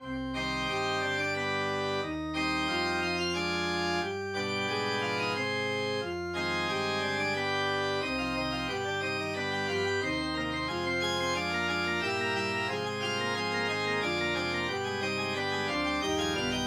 9.8. Organ Passacaglia in C minor, BWV 582, (a) mm. 9–12 and (b) mm. 181–84